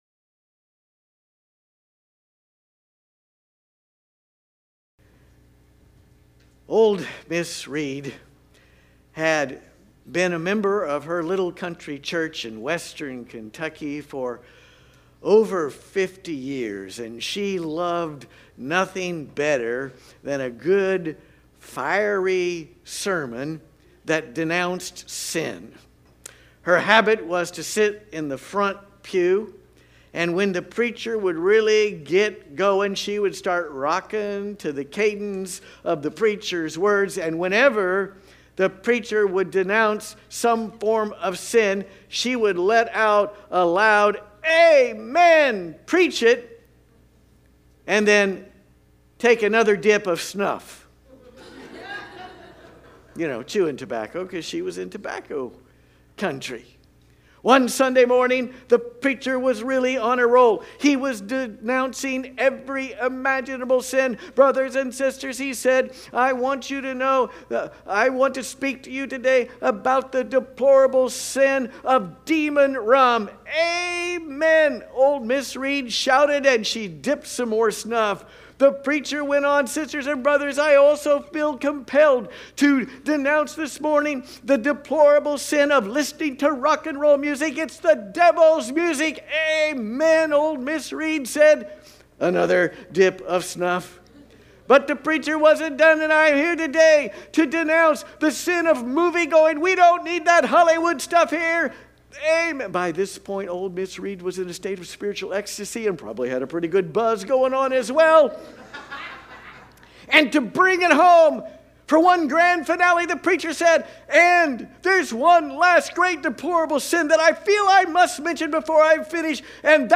If you're concerned about our nation, you won't want to miss this sermon.